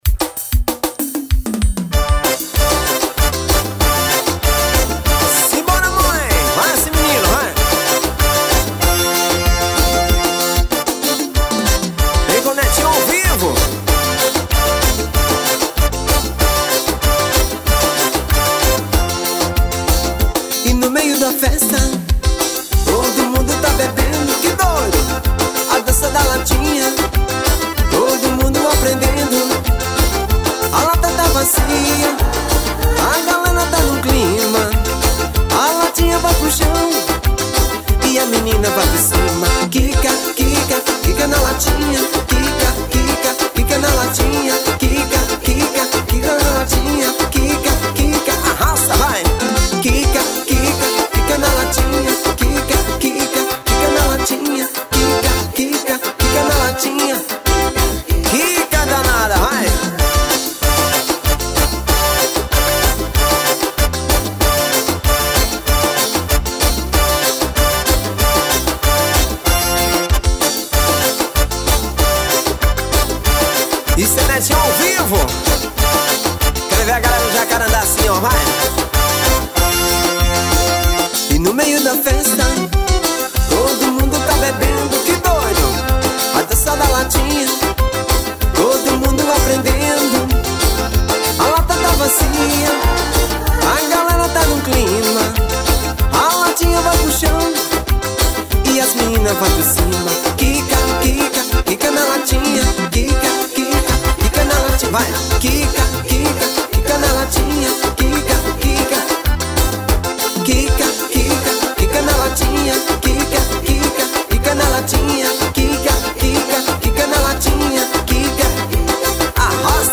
ao vivo